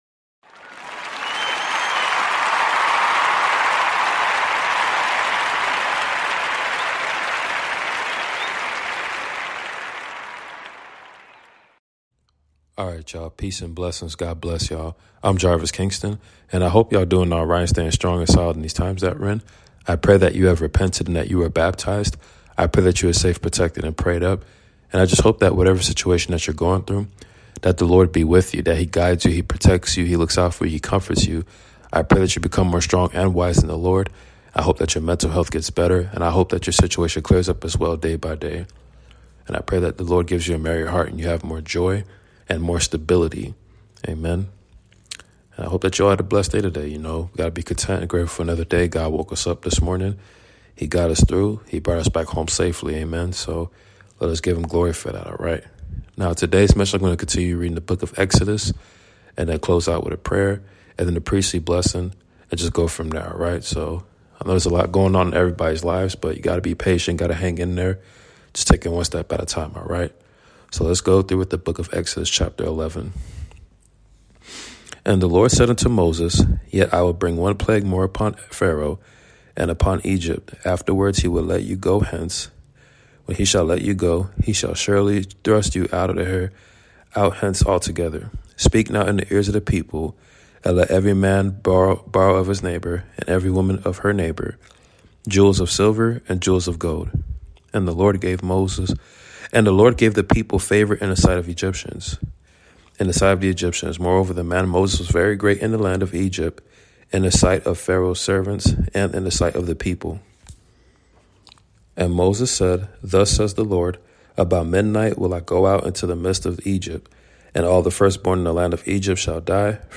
Book of exodus reading based on The Lord delivering Israel !